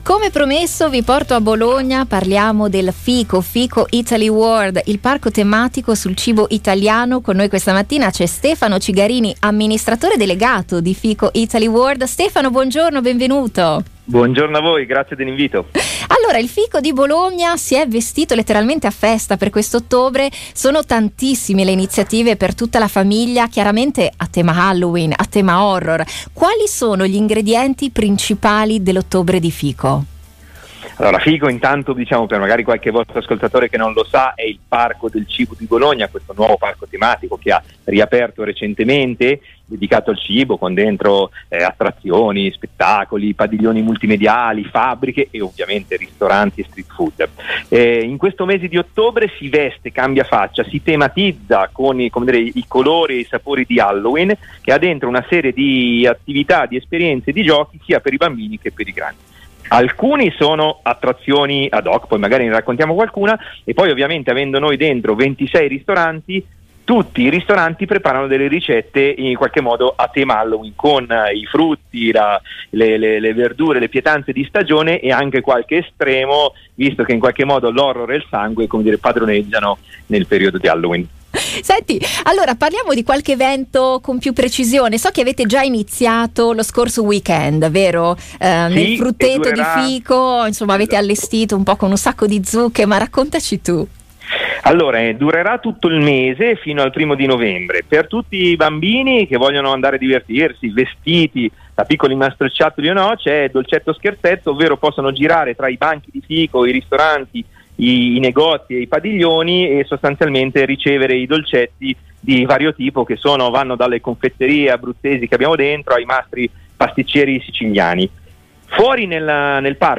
Le interviste di Passepartout: FICO festeggia Halloween tra gusto e divertimento